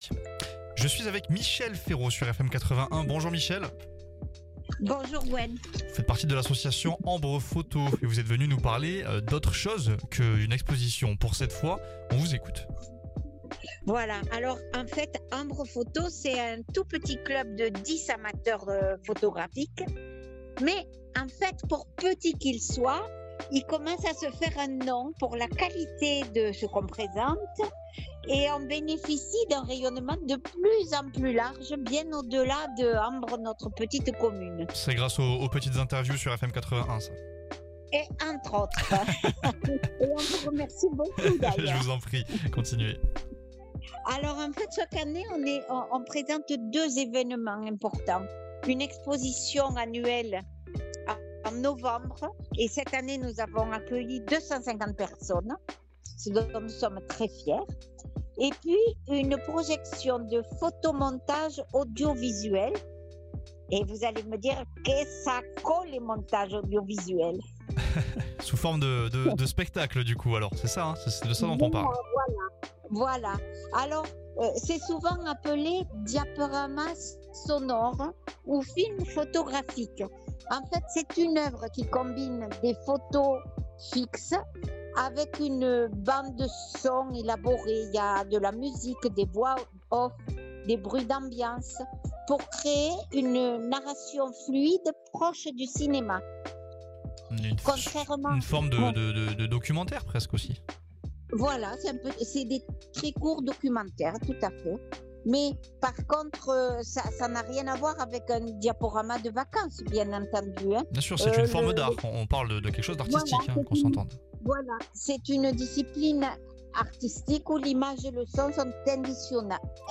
Découvrez le diaporama sonore ce dimanche 8 février Dans ce nouvel épisode de la chronique "Vie locale du Tarn"